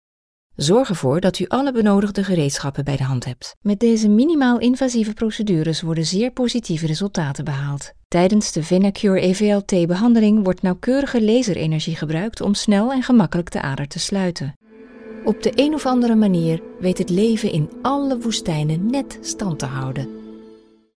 Dutch voice over